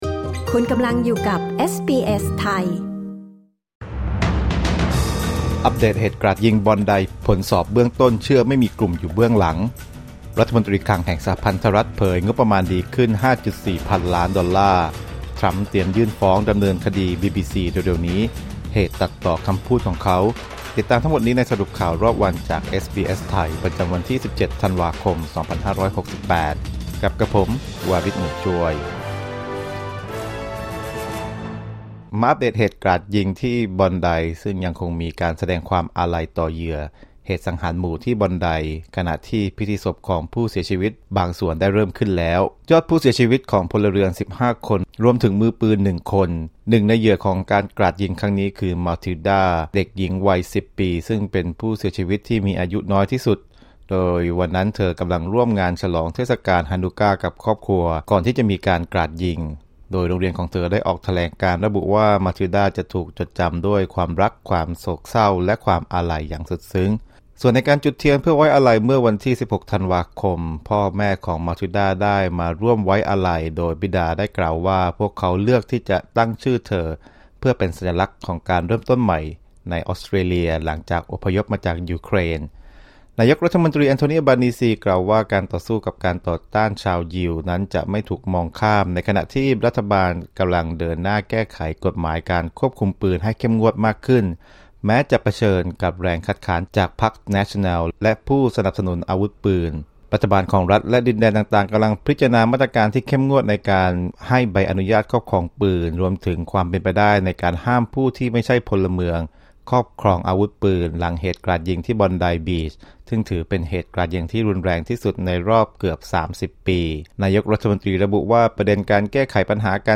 สรุปข่าวรอบวัน 17 ธันวาคม 2568